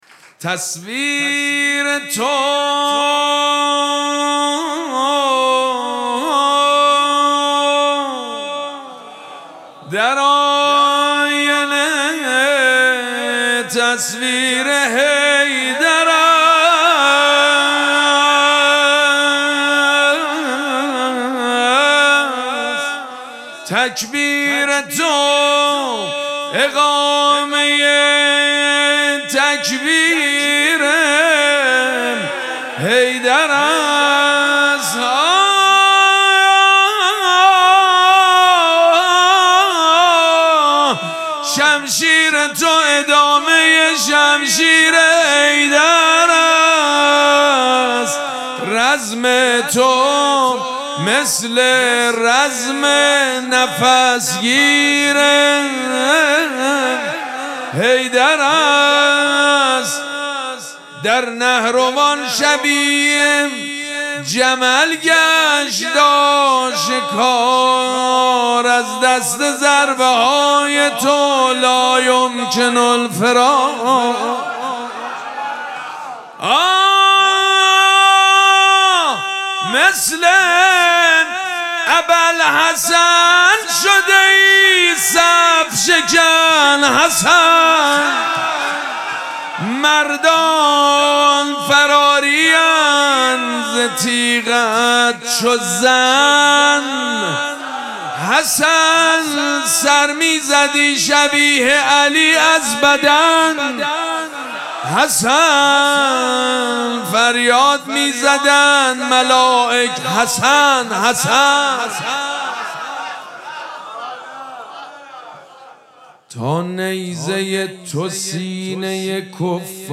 مراسم جشن شام ولادت امام حسن مجتبی(ع)
حسینیه ریحانه الحسین سلام الله علیها
مدح
حاج سید مجید بنی فاطمه